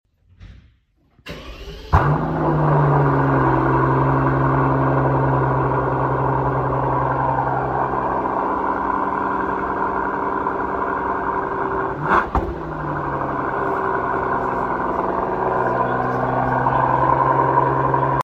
Mercedes S65 AMG V12 Final sound effects free download
Brutal Cold Start ! 🤯 Exhaust Sound Pure Sound La Mercedes S65 AMG V12 est une version haut de gamme de la Classe S, équipée d’un moteur V12 biturbo de 6,0 litres, développant environ 630 chevaux.